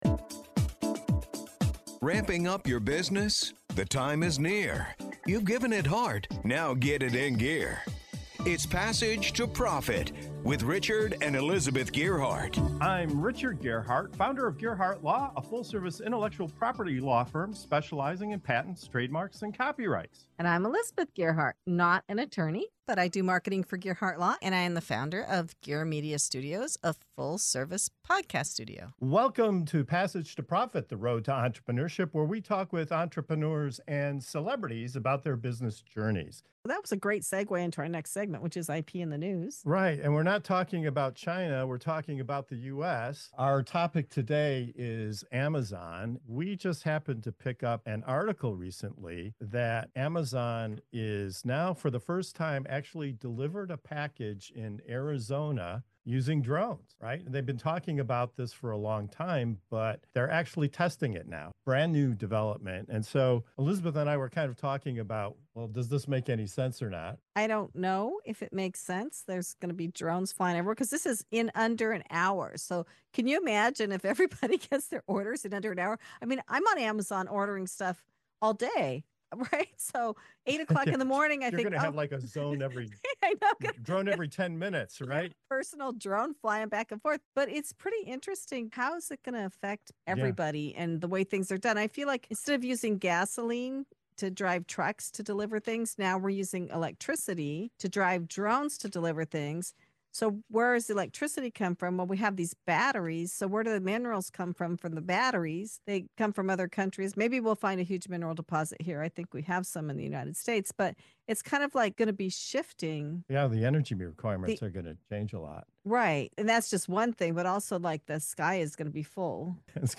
In this segment of Intellectual Property News on Passage to Profit Show, we dive into the surprising realities of drone delivery, from sky-high traffic to gesture-recognition patents that let you wave packages in like an air marshal. Our panel weighs in on the tech, the logistics, and the legal angles behind Amazon’s airborne ambitions.